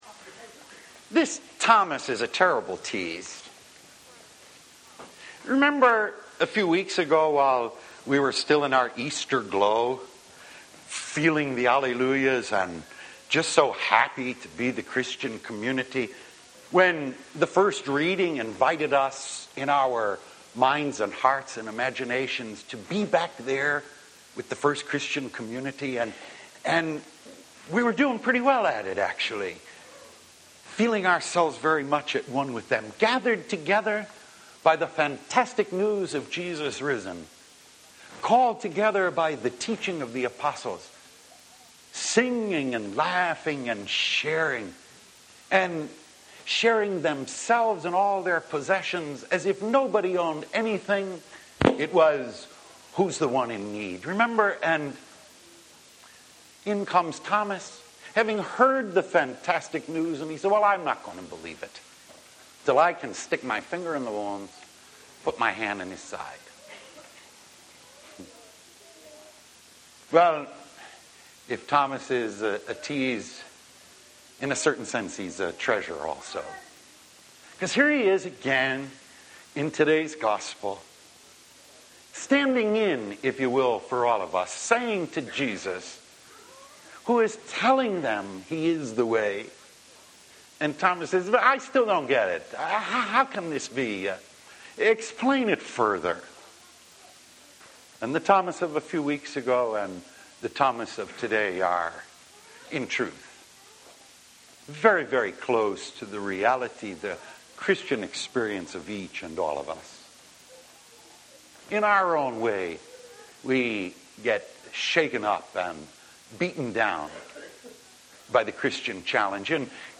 5th Sunday of Easter « Weekly Homilies
Originally delivered on May 9, 1993